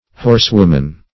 Search Result for " horsewoman" : Wordnet 3.0 NOUN (1) 1. a woman horseman ; The Collaborative International Dictionary of English v.0.48: Horsewoman \Horse"wom`an\, n.; pl.